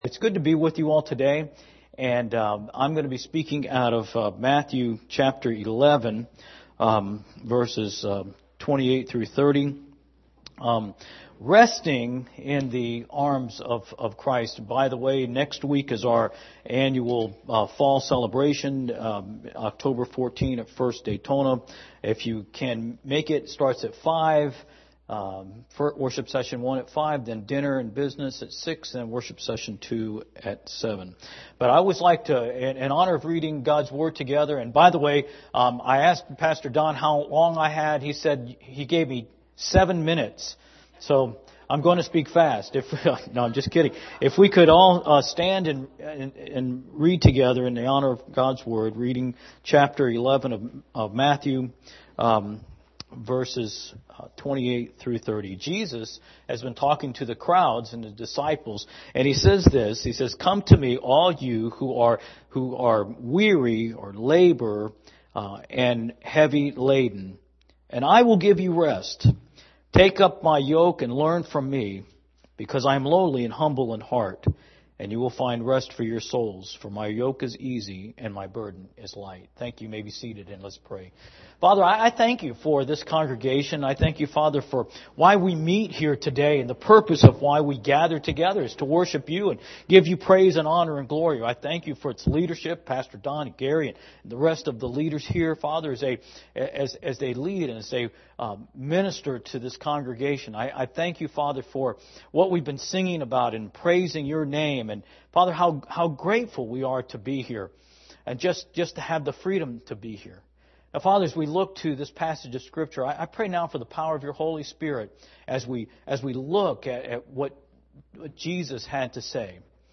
Morning Sermon Matthew 11:28-30
sermon10-7-18am.mp3